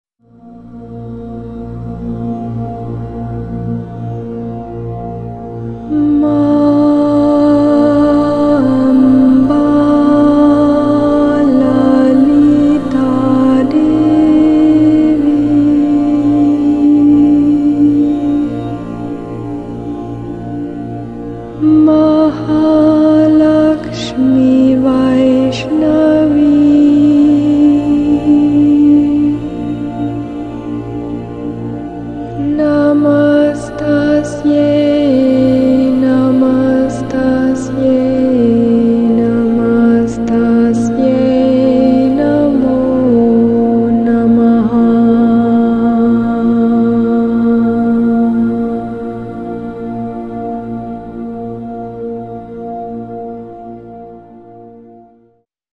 Sublime & spacious vocal chants to the Divine Mother - Devi.